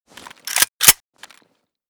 akm_unjam.ogg